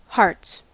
Ääntäminen
Ääntäminen US UK : IPA : /hɑː(ɹ)ts/ Haettu sana löytyi näillä lähdekielillä: englanti Hearts on sanan heart monikko.